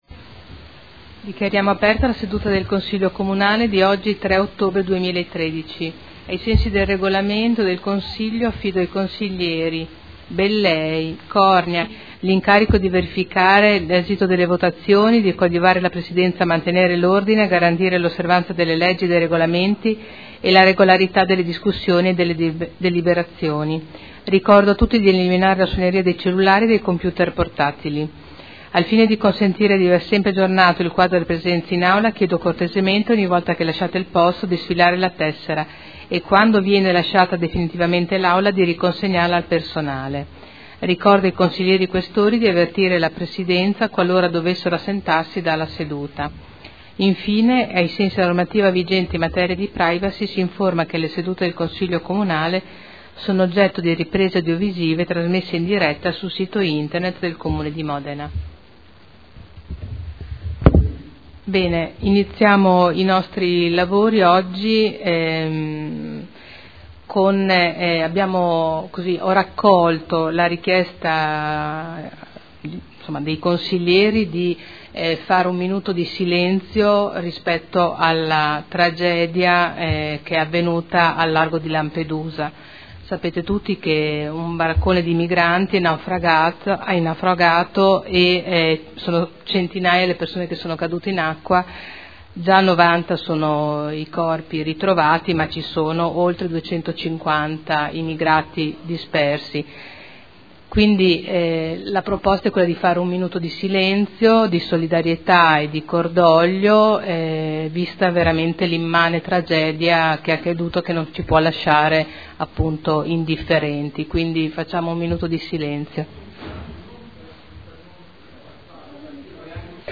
Seduta del 03/10/2013. Apertura lavori. Minuto di silenzio rispetto alla tragedia che è avvenuta al largo di Lampedusa.